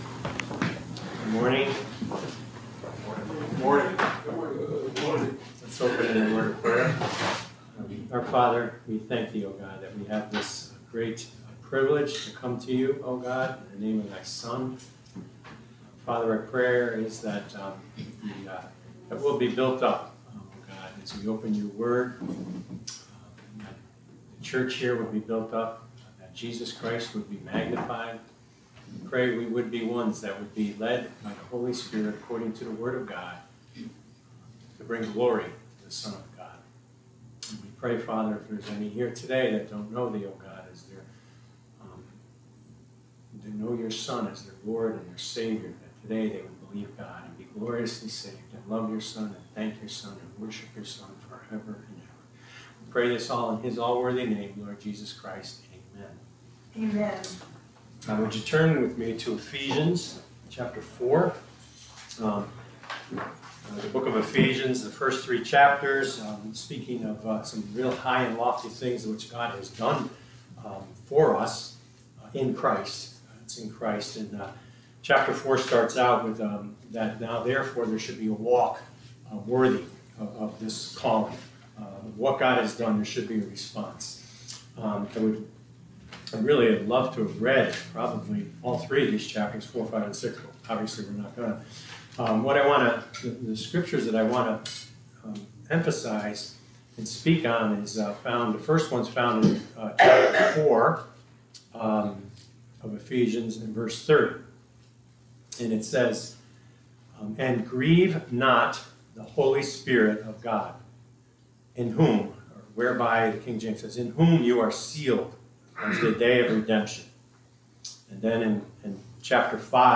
Role of the Holy Spirit Passage: Ephesians 4 Service Type: Sunday Afternoon « 08.21.22